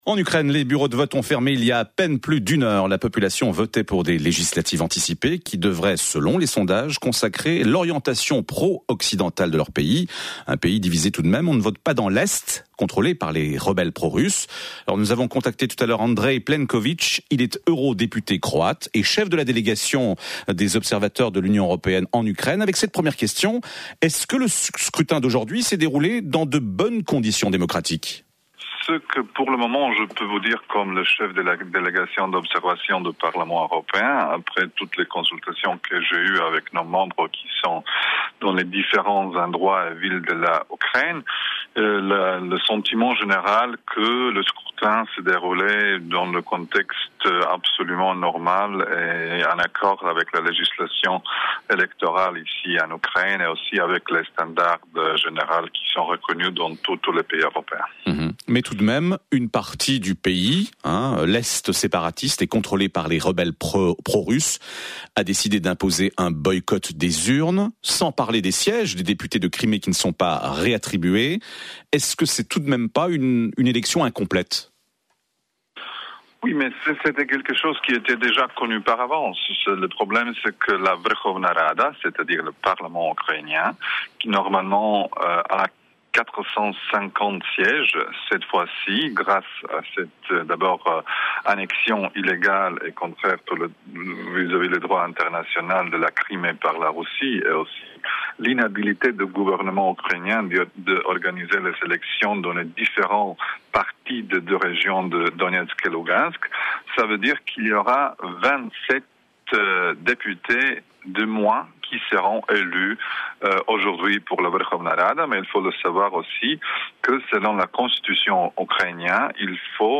Nakon zatvaranja biračkih mjesta u Ukrajini, prvi dojmovi voditelja promatračke misije Europskog parlamenta Andreja Plenkovića za švicarski radio